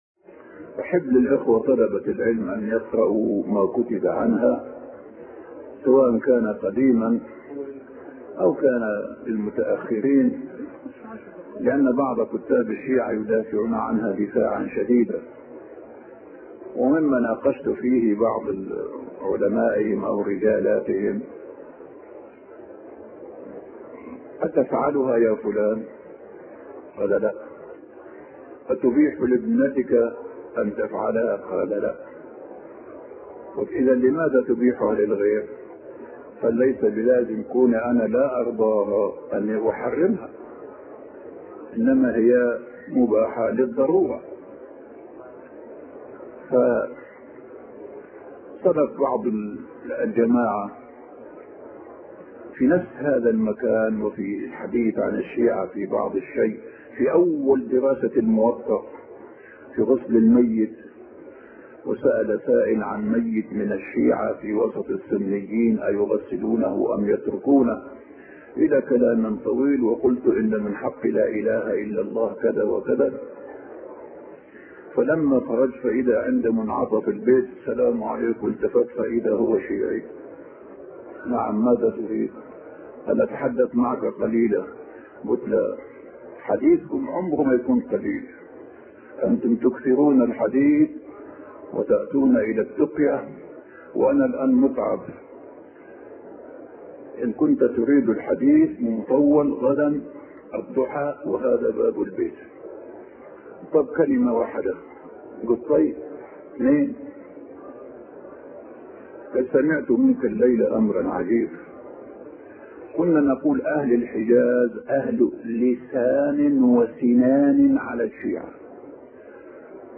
أرشيف الإسلام - أرشيف صوتي لدروس وخطب ومحاضرات الشيخ عطية محمد سالم